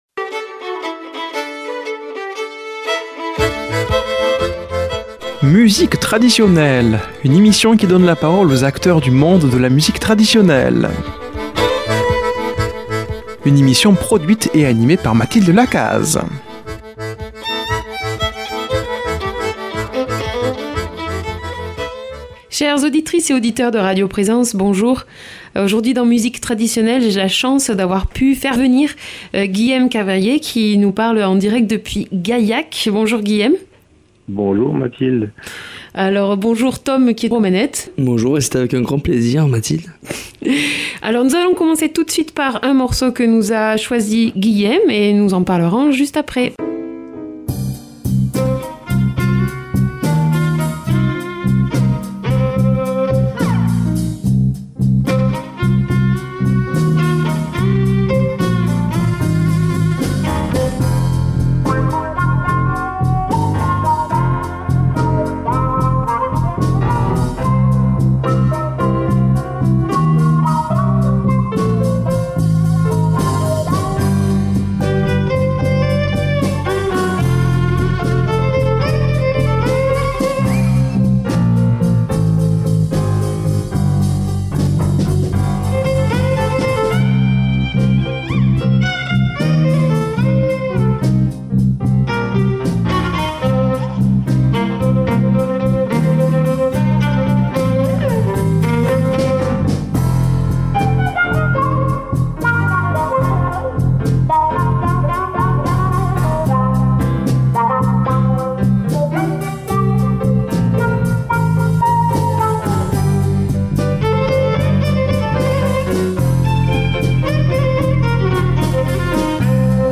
Nouvelle série d’émissions Musique Traditionelle